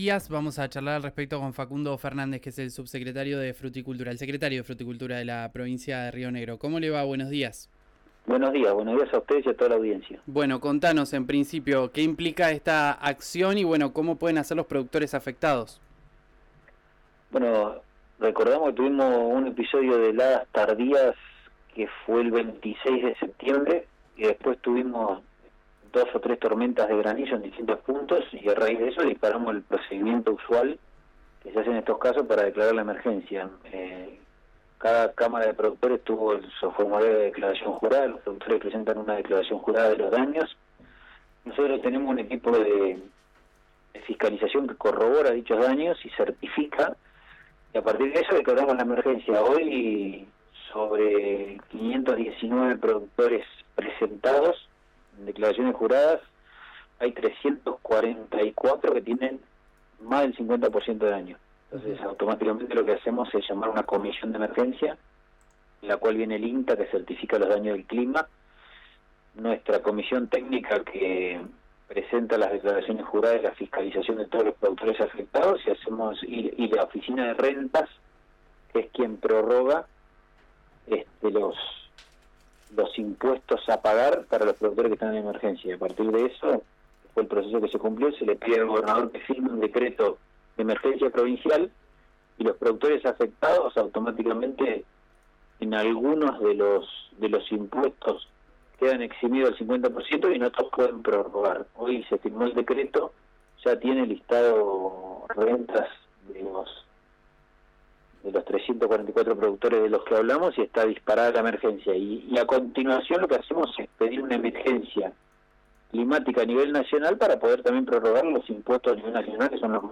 Escuchá a Facundo Fernández, secretario de Fruticultura, en RÍO NEGRO RADIO: